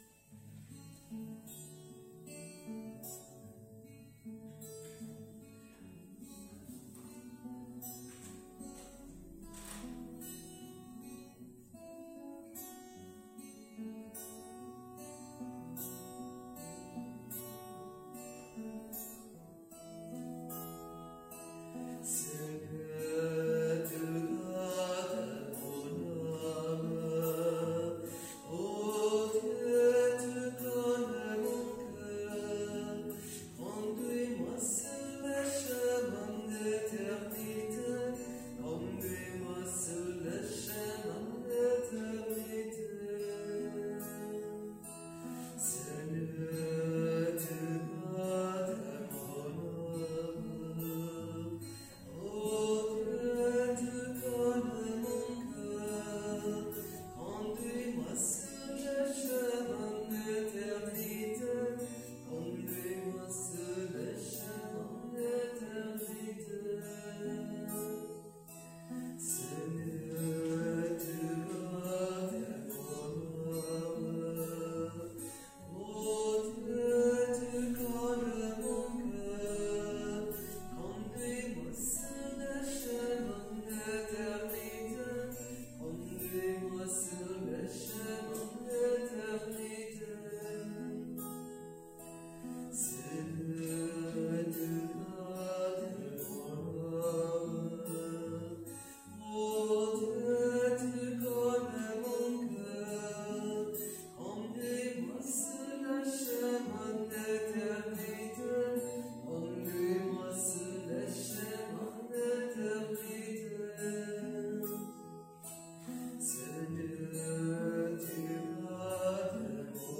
Pregària de Taizé a Mataró... des de febrer de 2001
Ermita de Sant Simó - Diumenge 30 de gener de 2022